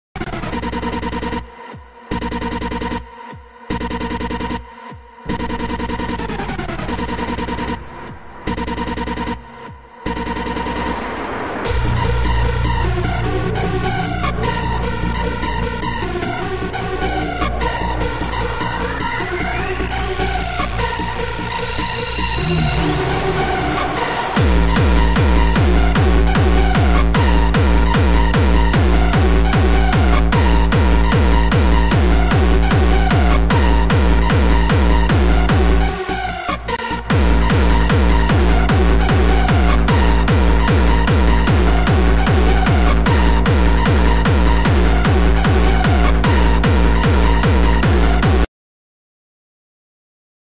jumpstyle ID ROBLOX AUDIO WORKING sound effects free download